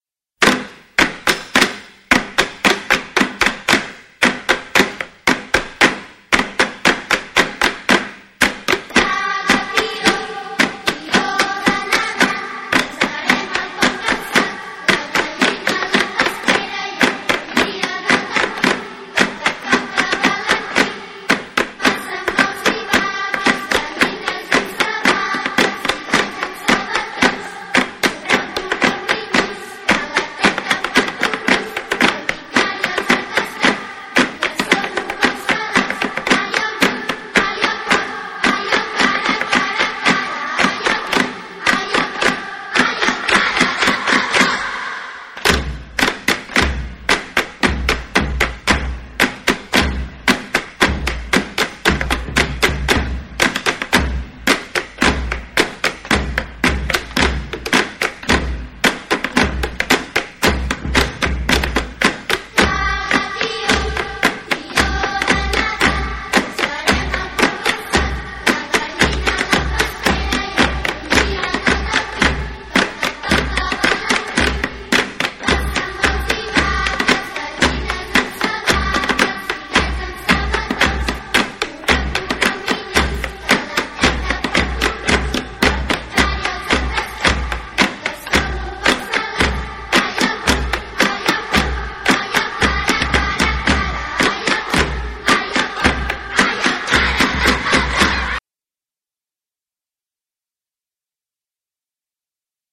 base instrumental
Caga-Tió-base.mp3